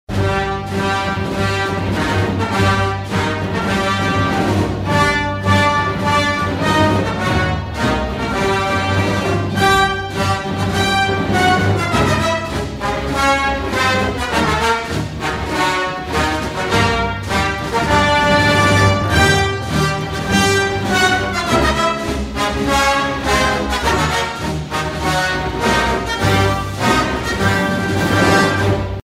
Саундтреки [70]